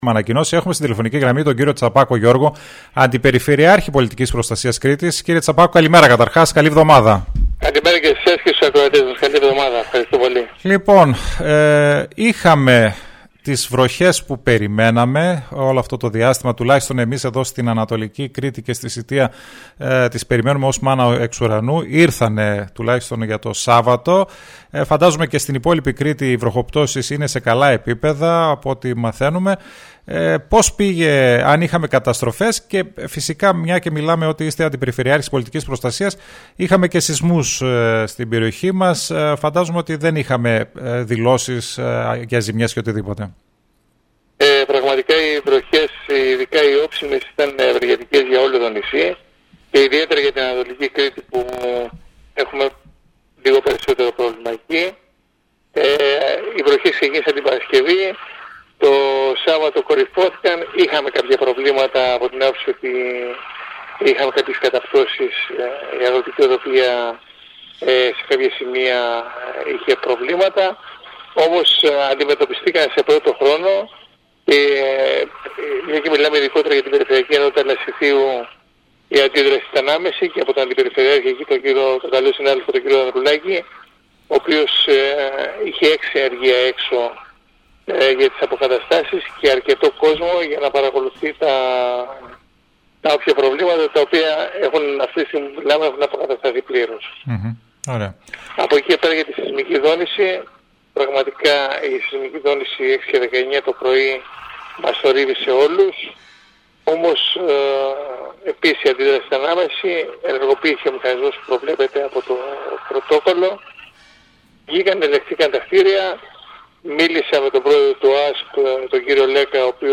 ΣΥΝΕΝΤΕΥΞΕΙΣ
Ο αντιπεριφερειάρχης πολιτικής προστασίας Τσαπάκος Γιώργος μιλώντας στην πρωινή εκπομπή του Style 100 άφησε ανοιχτό την ανακοίνωση αναβάθμισης του πυροσβεστικού κλιμακίου Σητείας τις επόμενες εβδομάδες από το υπουργείο